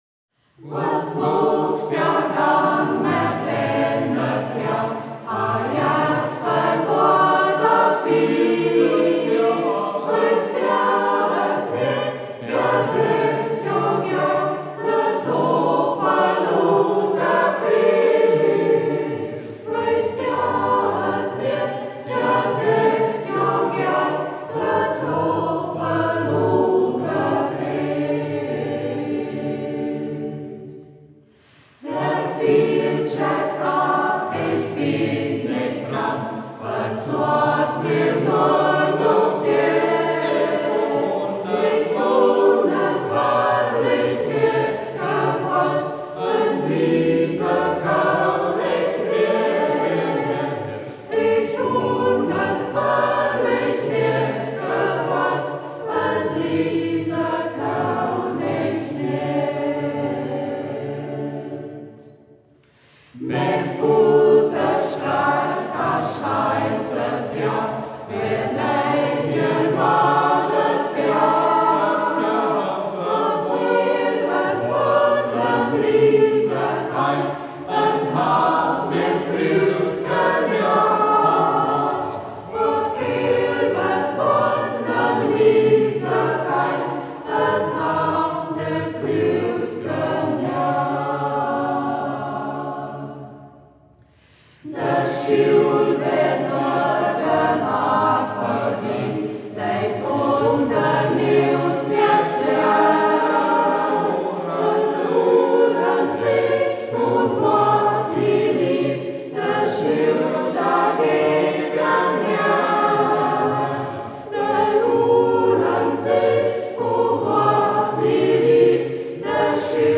Singkreis Kampestweinkel • Ortsmundart: Braller